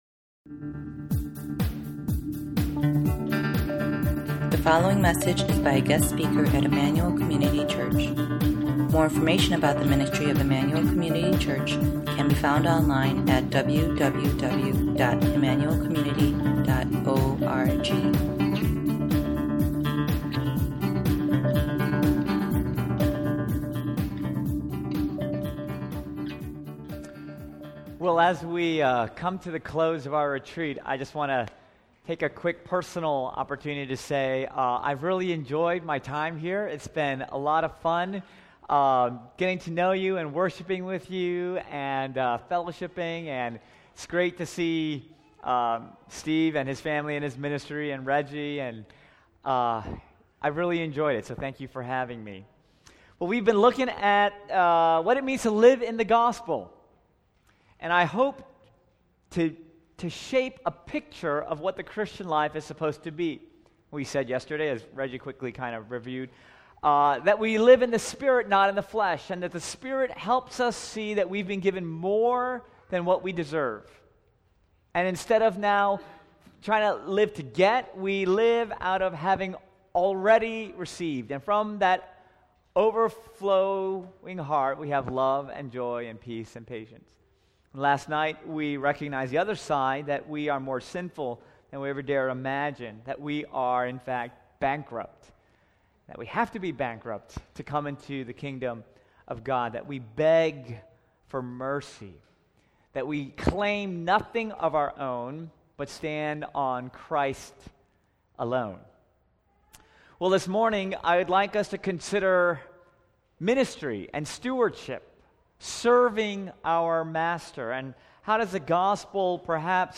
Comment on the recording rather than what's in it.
This is the final message from the 2013 ICC Retreat.